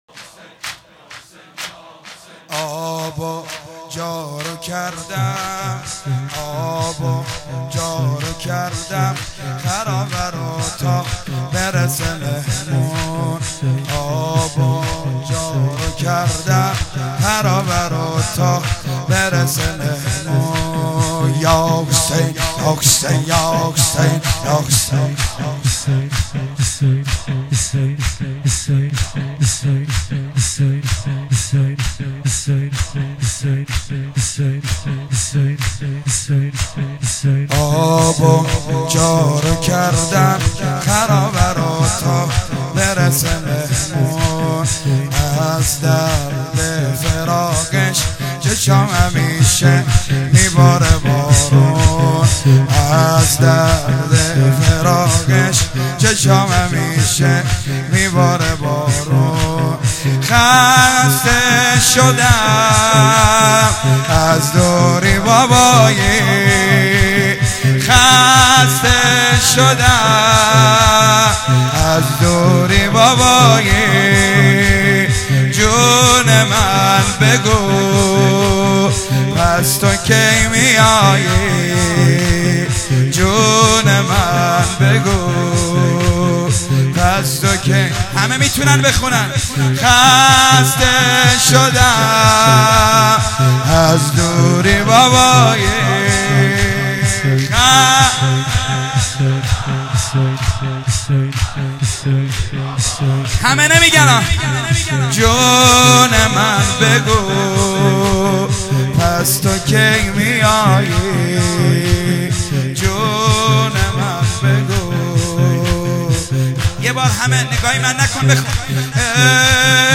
فایل های مدح وسینه زنی
4-زمینه